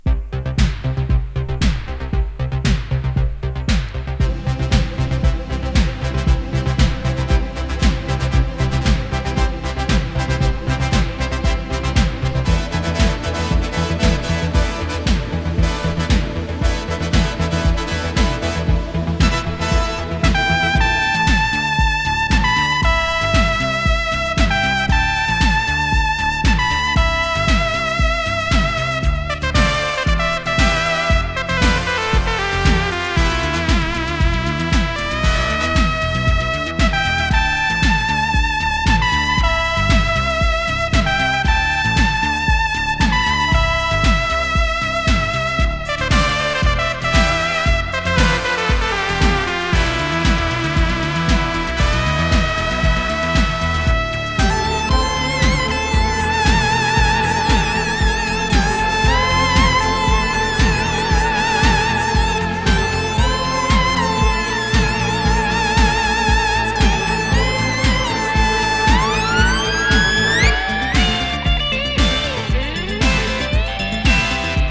チープでファンキー。